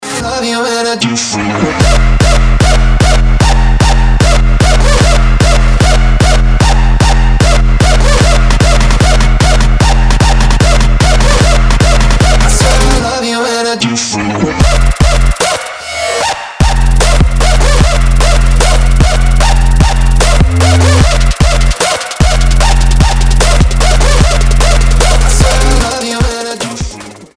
• Качество: 320, Stereo
мужской вокал
dance
Electronic
EDM
Trap
качающие
Bass
Мозговыносной трэпчик